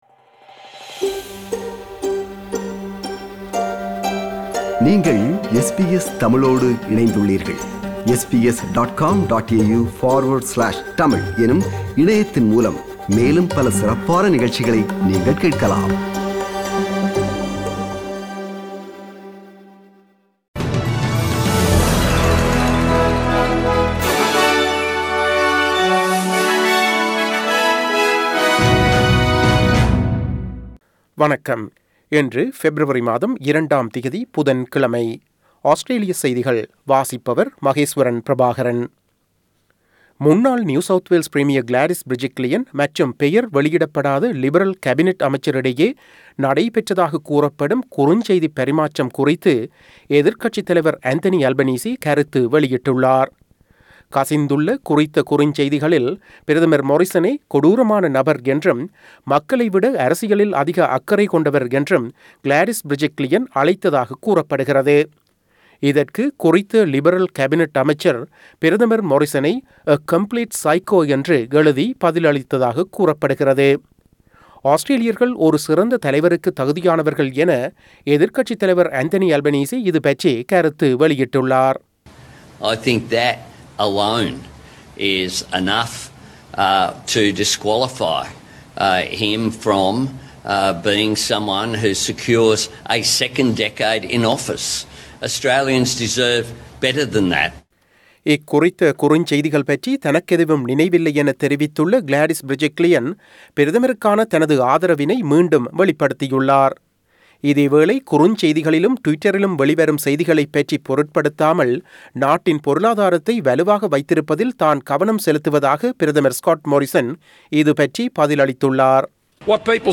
Australian news bulletin for Wednesday 02 February 2022.